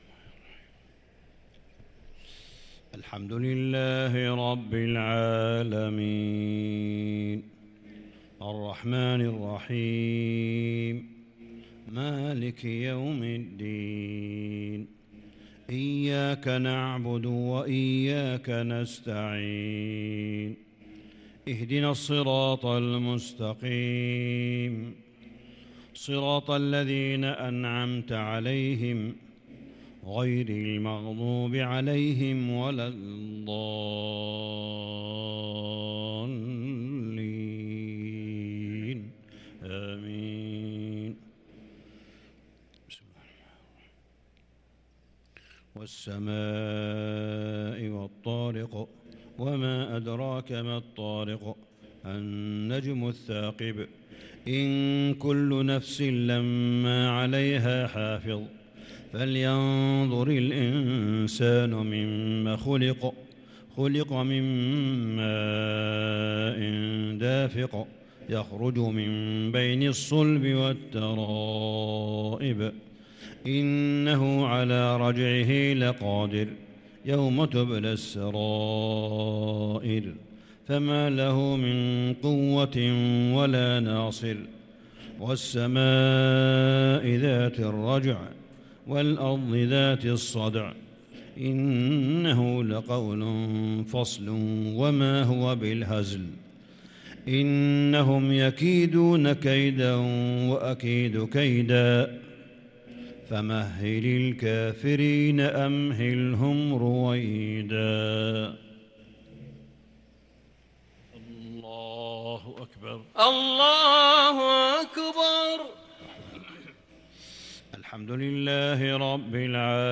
صلاة العشاء للقارئ صالح بن حميد 7 رمضان 1443 هـ